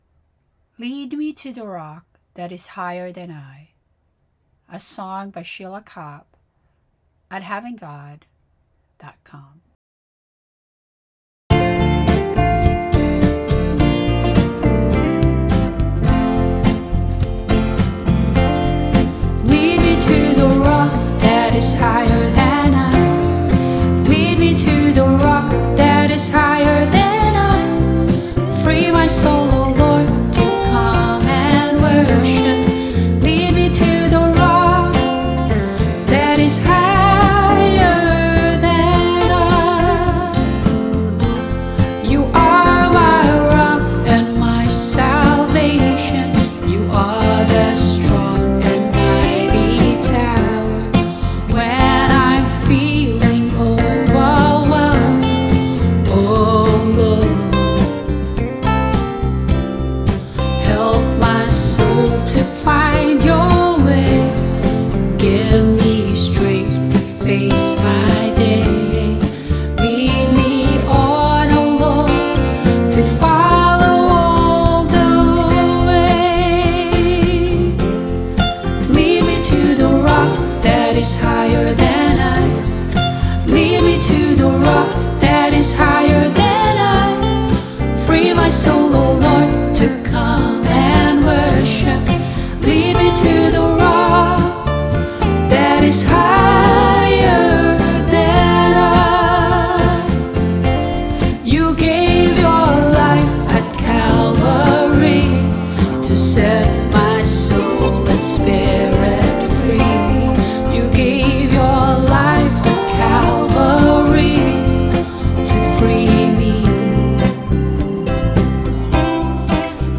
Vocals and Band-In-A-Box Arrangement